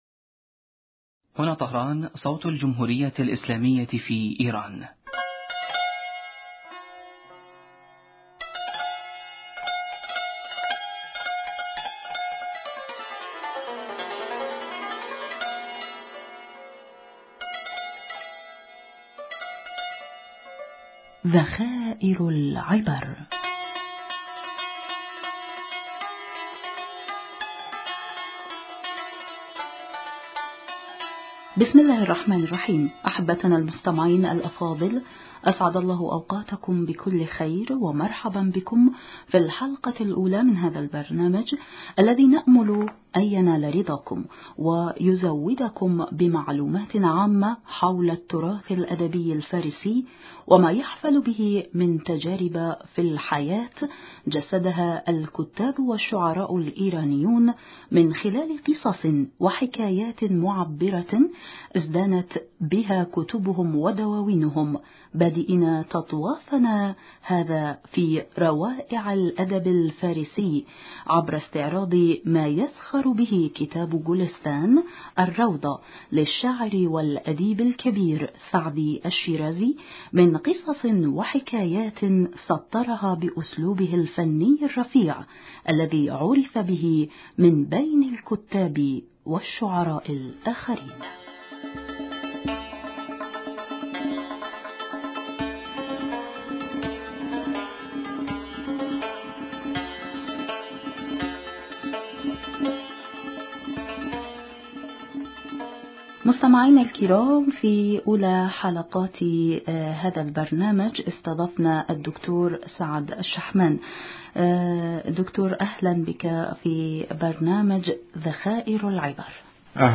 ضيف البرنامج